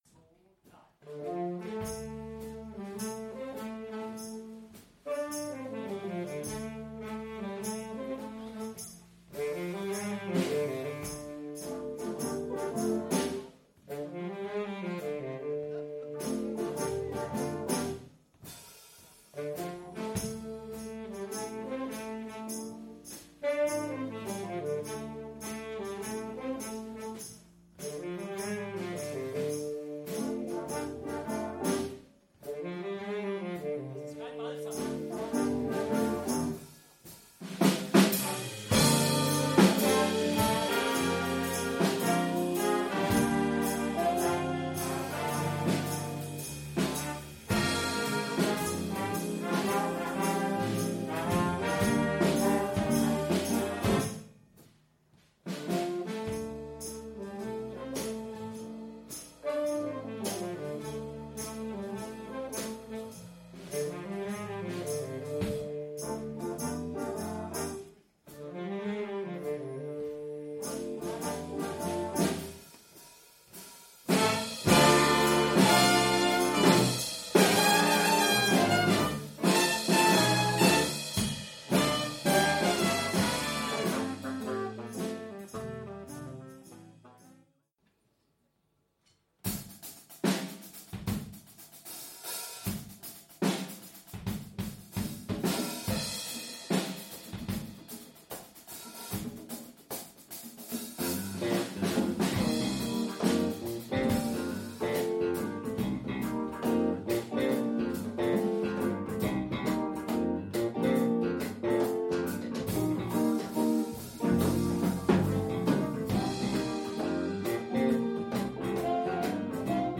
Lehre und Forschung mit Engagement dem Big Band Jazz.
Aus dem musikalischen Potenzial der Universität Rostock hat sich ein erfahrenes, gut eingespieltes 24-köpfiges Ensemble mit einem breit aufgestellten Programm geformt. Bei den wöchentlich stattfindenden Proben werden Stücke aus diversen Musikgenres wie Swing und Blues, Jazzrock und Funk, Rock, Pop und Reggae in teils eigenen Arrangements eingeübt. UniverCity bietet ein jazzig-buntes Programm mit raffinierten, teils eigenen Arrangements und knackigen Einlagen der Solisten.